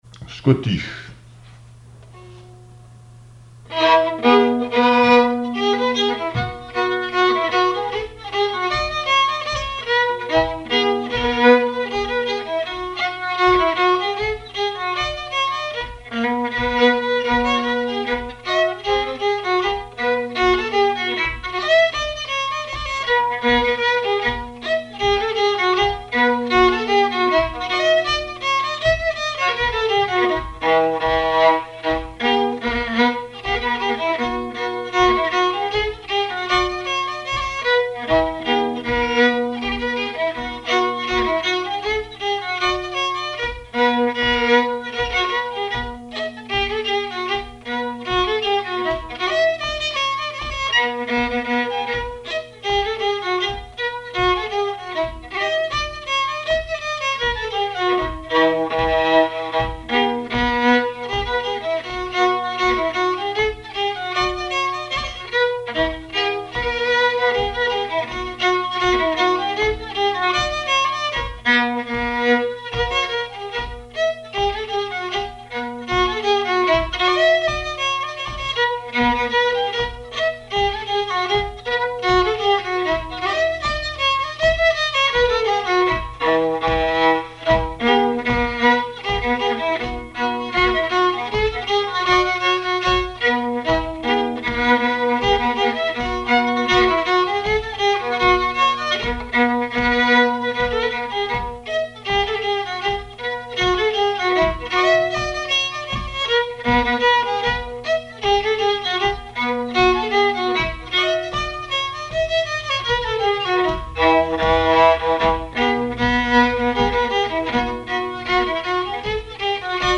violoneux, violon
scottich trois pas
Pièce musicale inédite